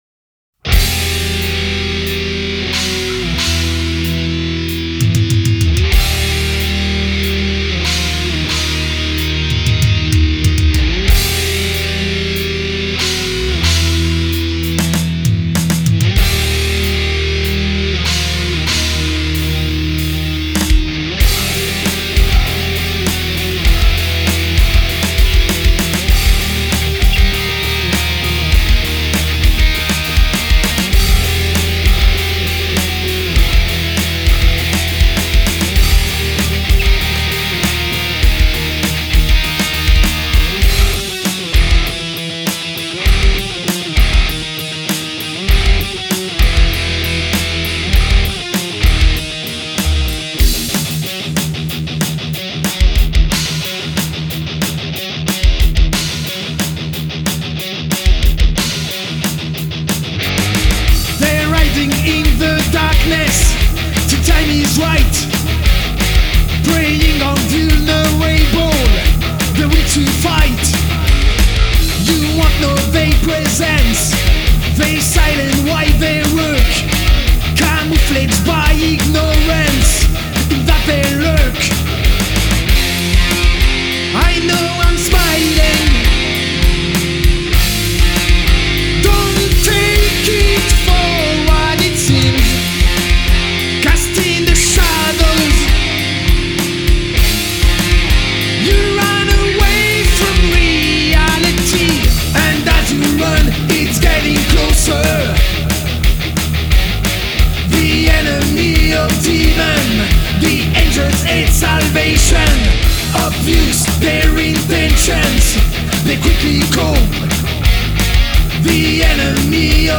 enregistrés aux salles de répètition
Guitares et chant
Batterie
Basse